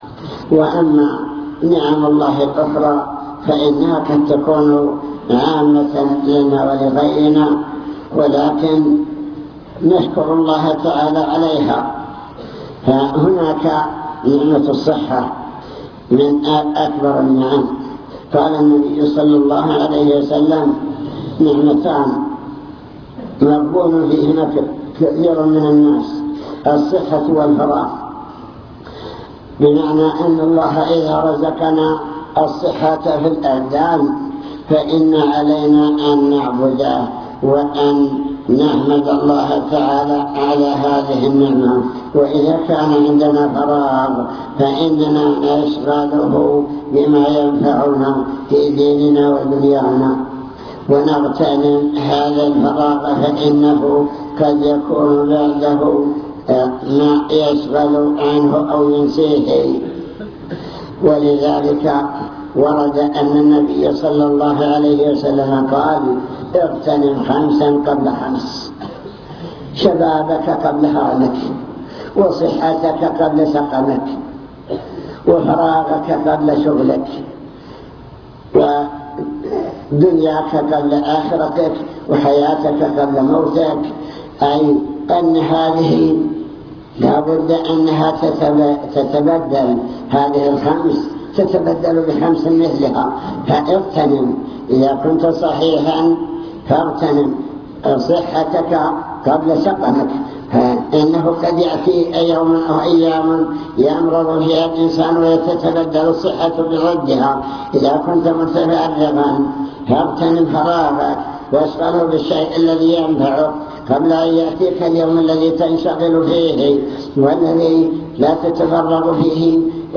المكتبة الصوتية  تسجيلات - محاضرات ودروس  محاضرة بعنوان شكر النعم (3) نماذج لنعم الله تعالى على العالمين